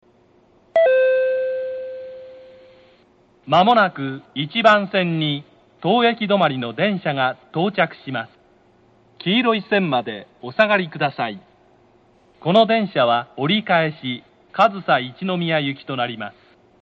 京葉地下ホームは地上ホームから６００ｍくらいも離れたところにあります。
１番線接近放送 折り返し上総一ノ宮行の放送です。
tokyo-keiyo-1bannsenn-sekkinn.mp3